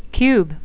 (cube)